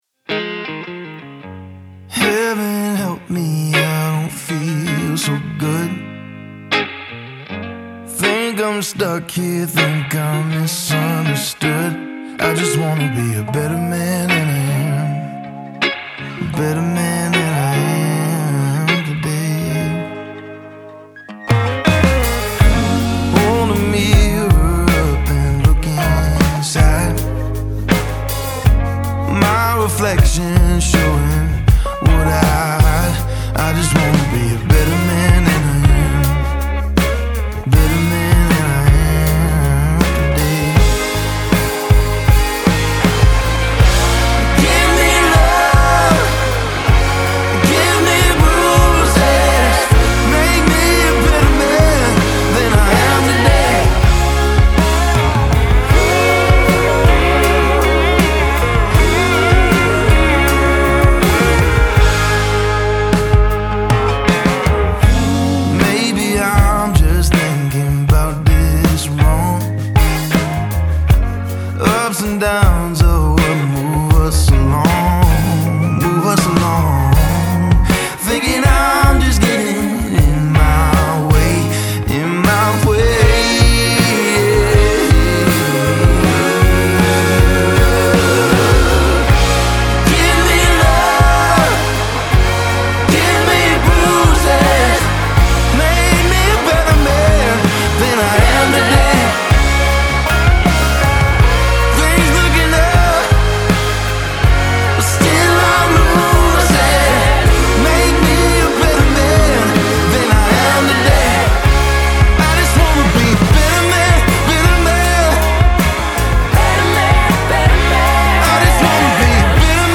Part crooner, part troubadour, all storyteller.